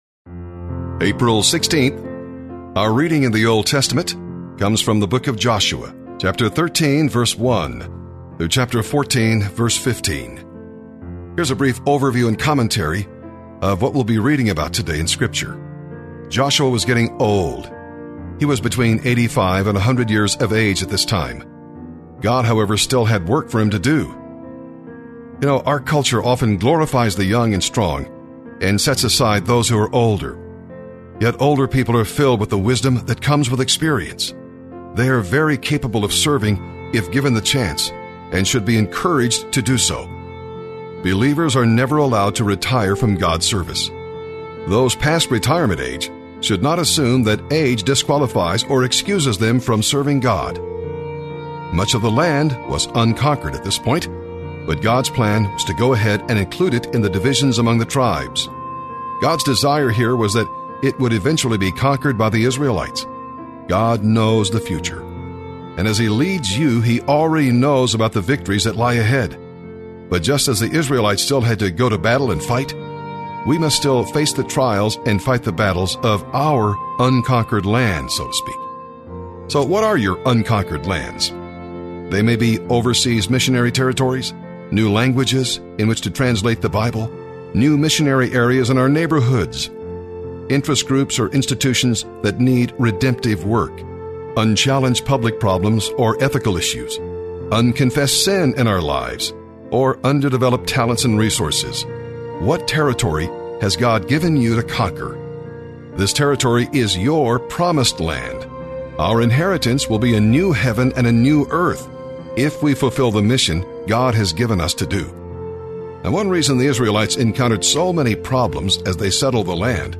April 16th Bible in a Year Readings